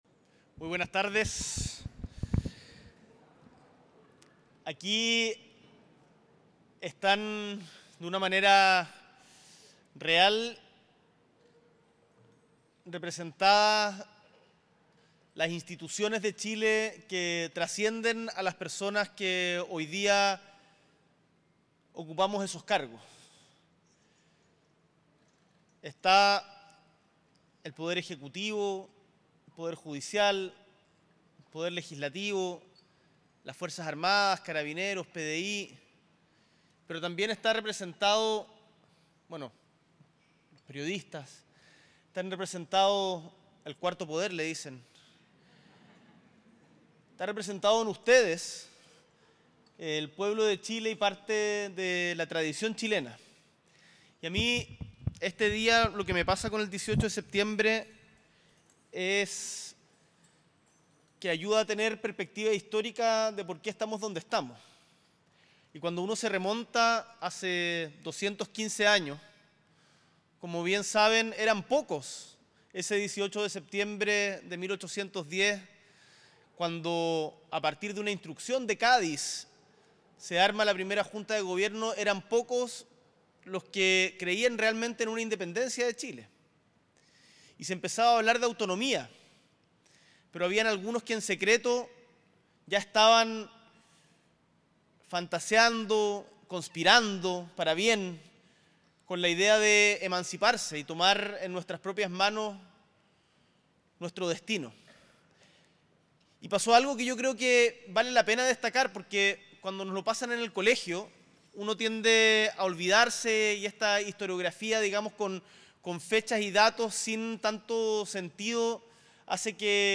S.E. el Presidente de la República, Gabriel Boric Font, encabeza tradicional Esquinazo en el Palacio de La Moneda junto a autoridades de Estado y de Gobierno